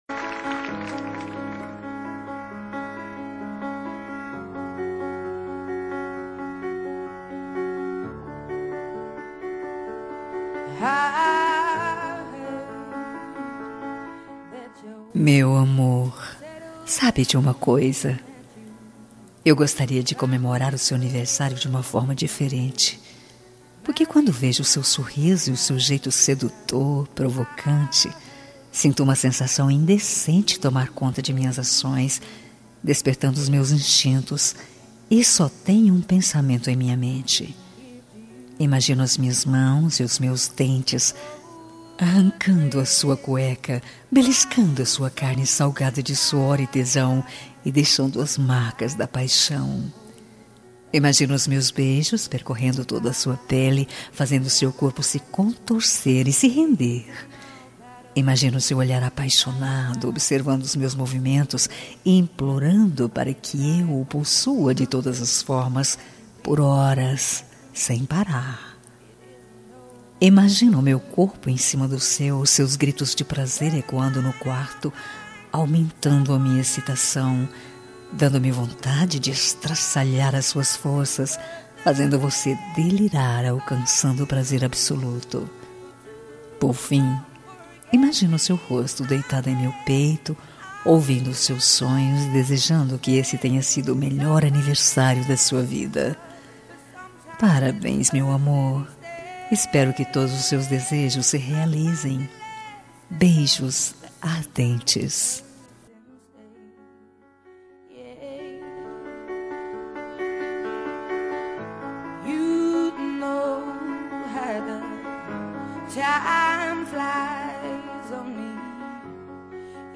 Telemensagem de Aniversário Romântico – Voz Feminina – Cód: 202046 – Picante